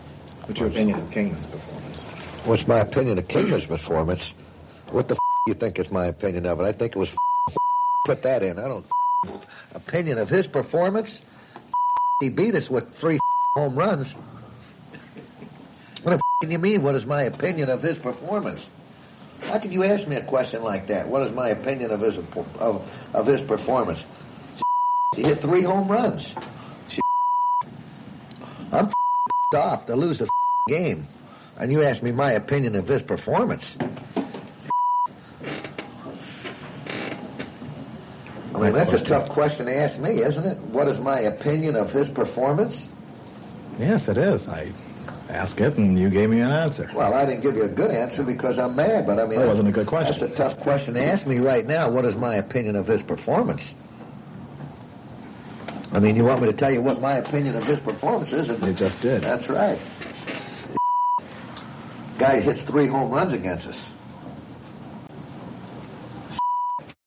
The legendary Los Angeles sportscaster Jim Healy is best remembered for the heyday of his nightly radio show from the late 1970s to the early '90s. Besides reporting the nuts and bolts of sports, Healy incorporated into the proceedings a conveyor belt of audio clips, taped voices of athletes, entertainers, politicians.